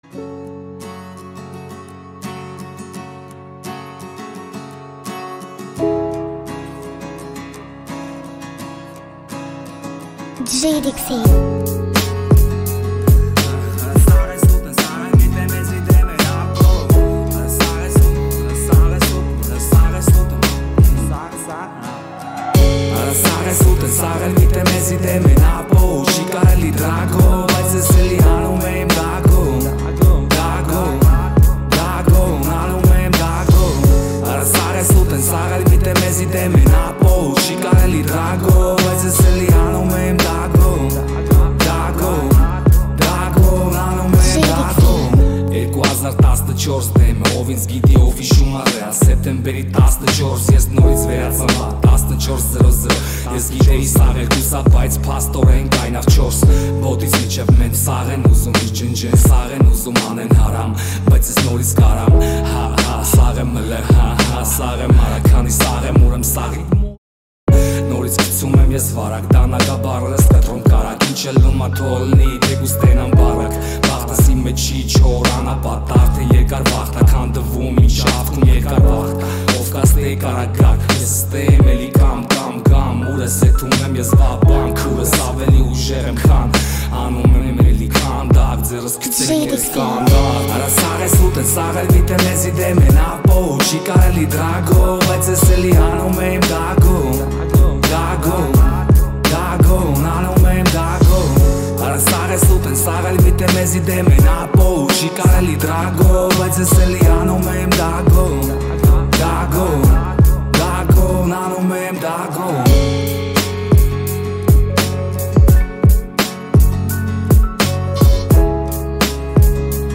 Haykakan rap